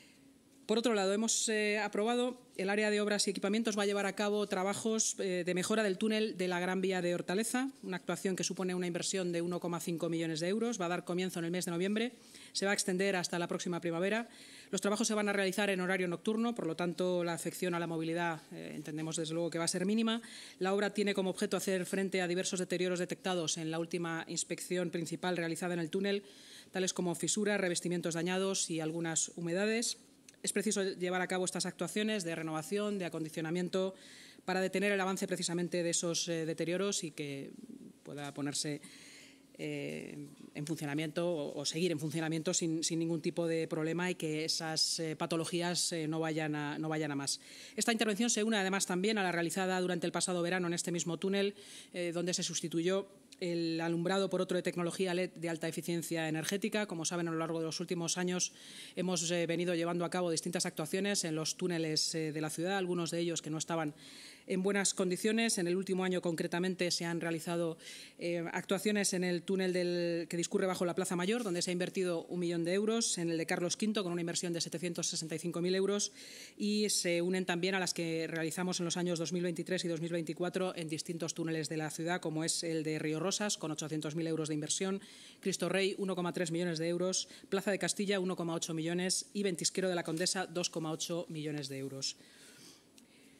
Así lo ha anunciado la vicealcaldesa y portavoz municipal, Inma Sanz, tras la reunión semanal de la Junta de Gobierno.